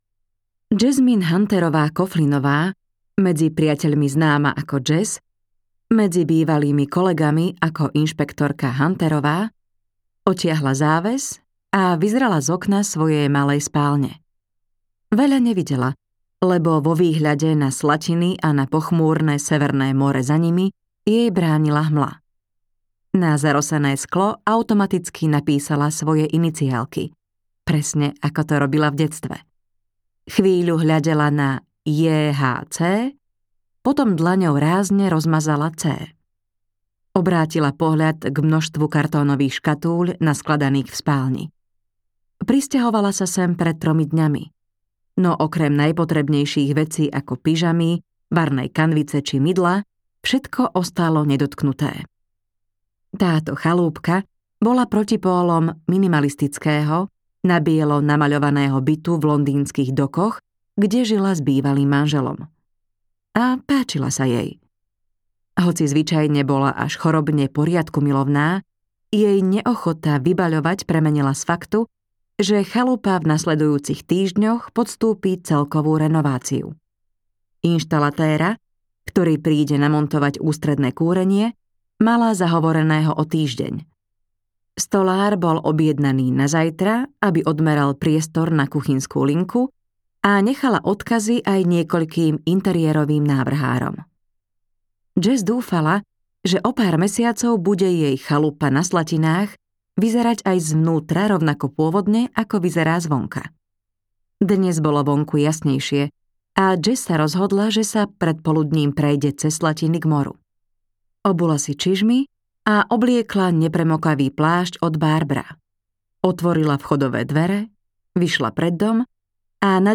Záhadné vraždy v internáte audiokniha
Ukázka z knihy